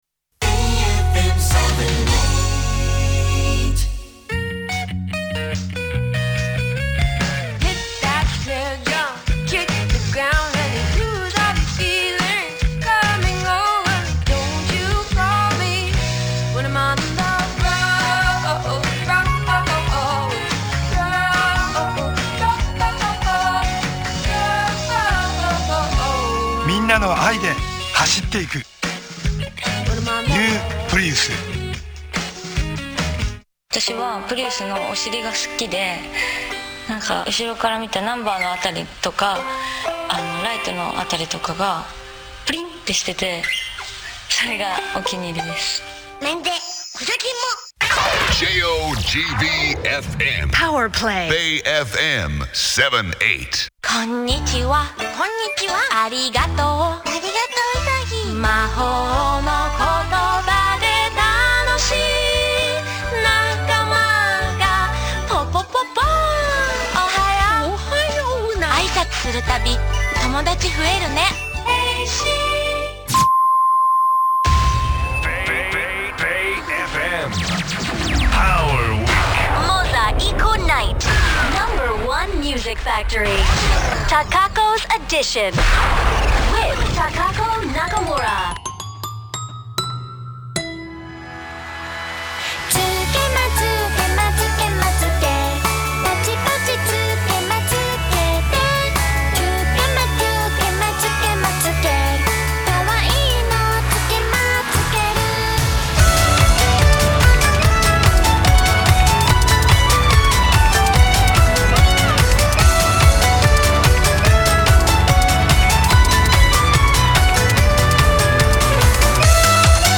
鍵盤押すと「べぇいえふえぇむ」だの「あいさつの魔法」だの「プリウスの広告」だの流れます。
サンプラーとしては異例の長さを流しているので、音質悪いですが、遊びなので気にしないっ！。